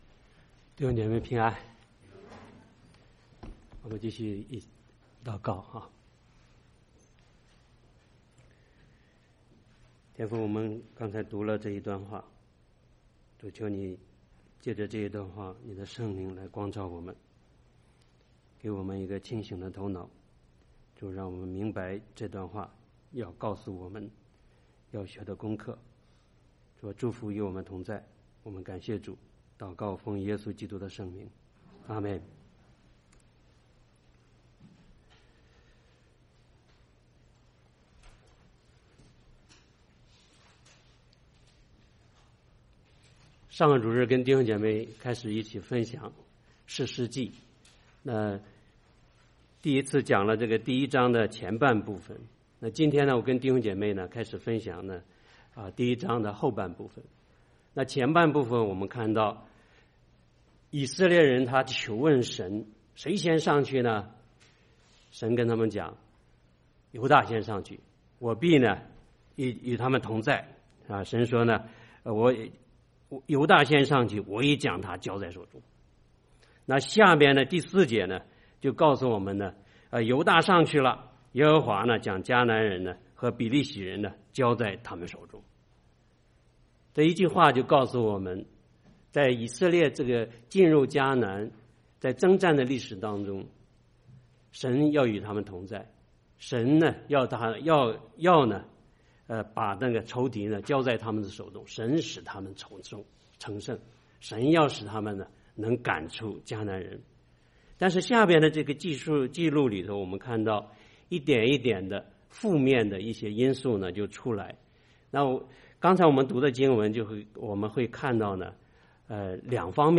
崇拜講道錄音